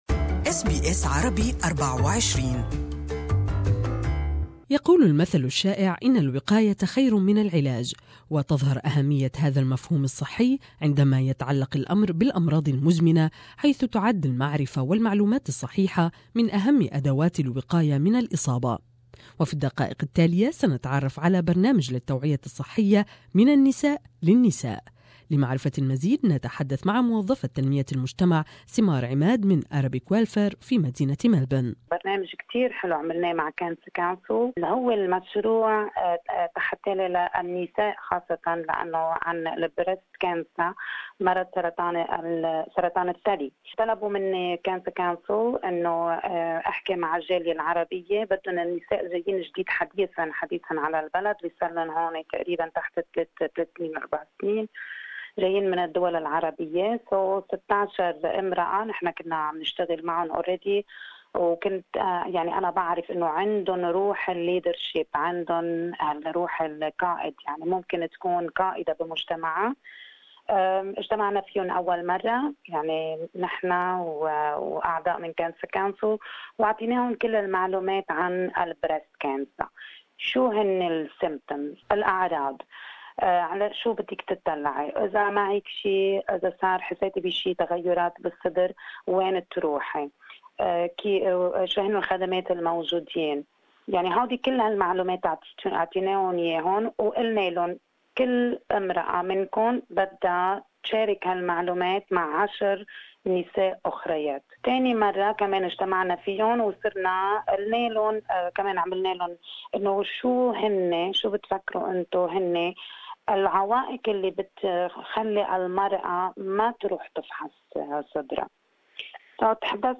More in this interview